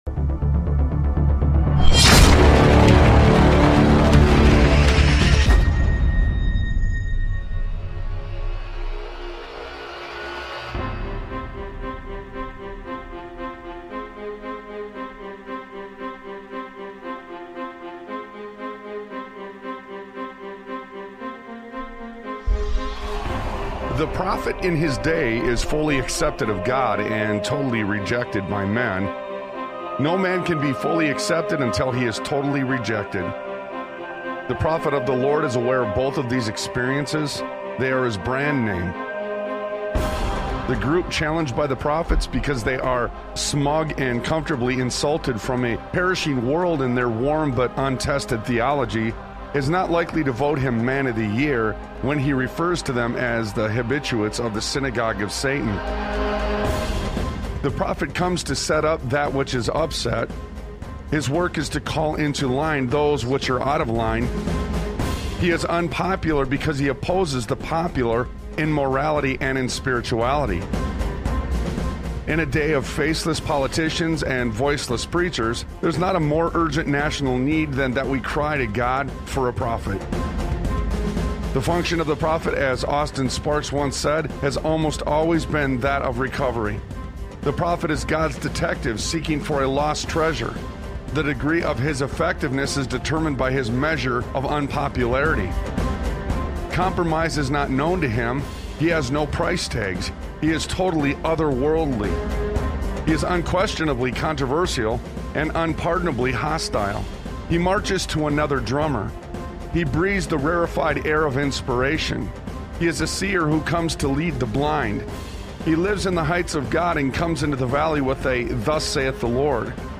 Talk Show Episode, Audio Podcast, Sons of Liberty Radio and Time To Pick Up The Hammer, Again! on , show guests , about Time To Pick Up The Hammer,Again, categorized as Education,History,Military,News,Politics & Government,Religion,Christianity,Society and Culture,Theory & Conspiracy